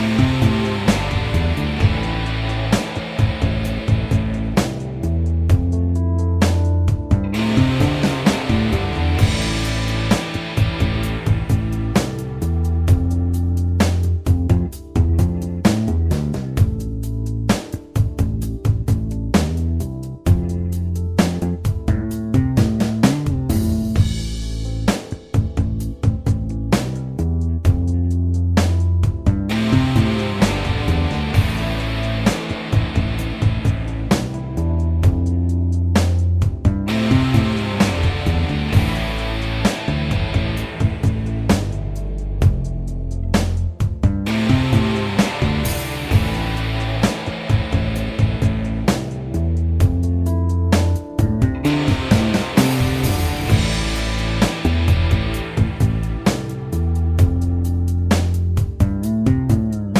no saxes Pop (2010s) 4:05 Buy £1.50